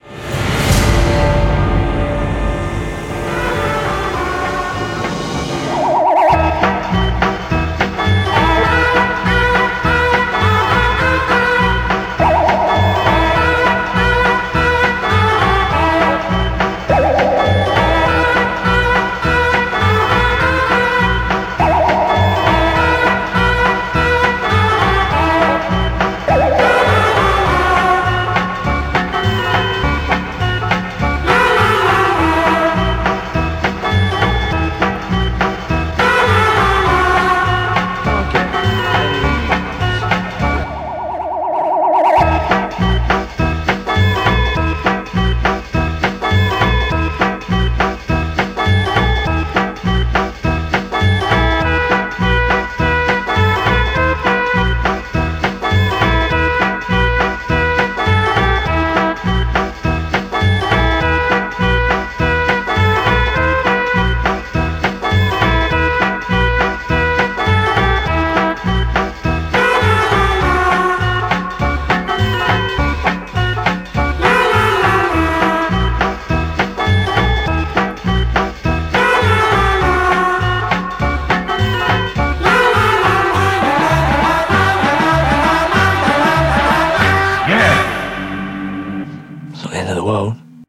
Саундтрек можно скачать с нашего сервера